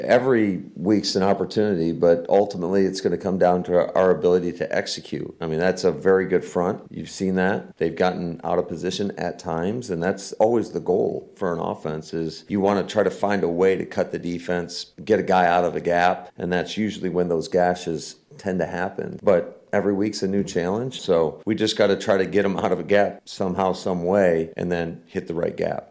(LEARFIELD) – Green Bay Packers coach Matt LaFleur covered a lot of topics when he spoke with the media on Thursday.